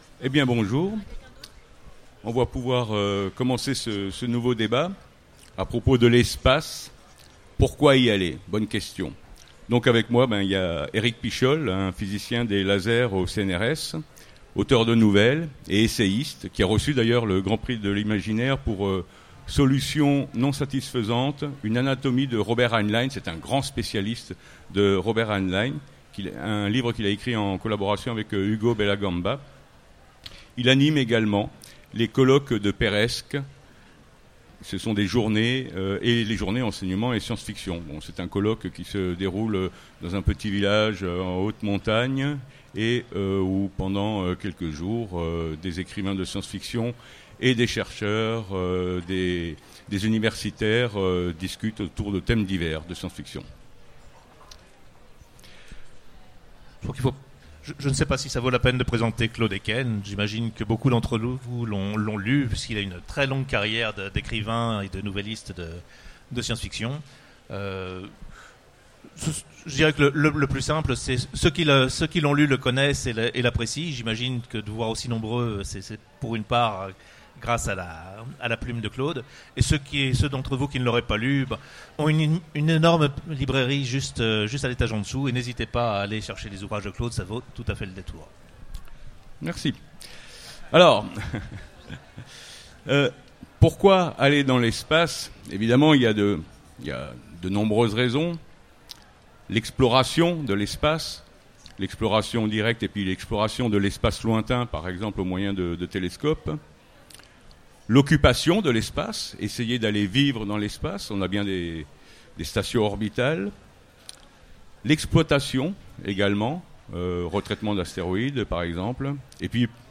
Utopiales 2016 : Conférence L’espace pourquoi y aller ?